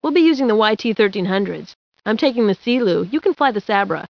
―Aeron Azzameen speaks to her brother Ace on an upcoming family assignment — (audio)